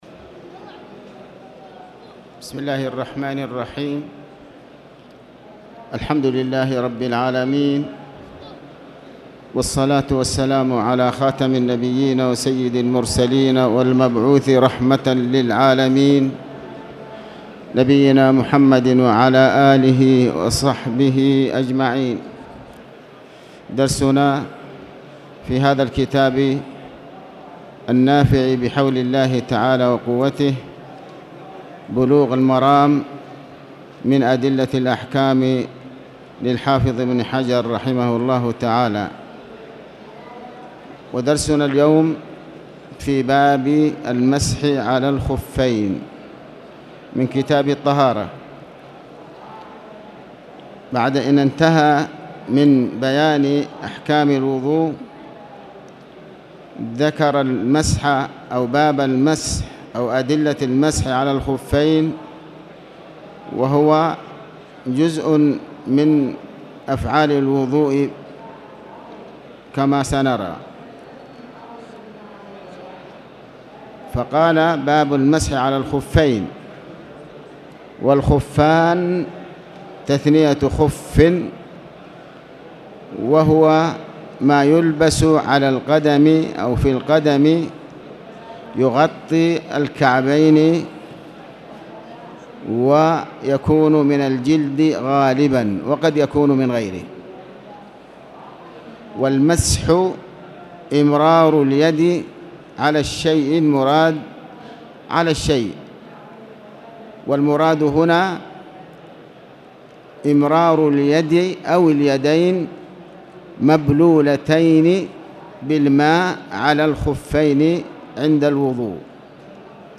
تاريخ النشر ٢٦ جمادى الأولى ١٤٣٨ هـ المكان: المسجد الحرام الشيخ